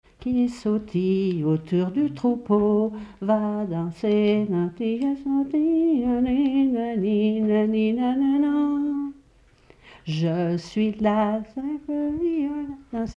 Saint-Julien-en-Genevois
Pièce musicale inédite